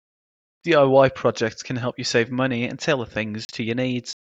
Read more Noun Verb Adj Frequency 32k Pronounced as (IPA) /ˌdi.aɪˈwaɪ/ Etymology Initialism of do it yourself.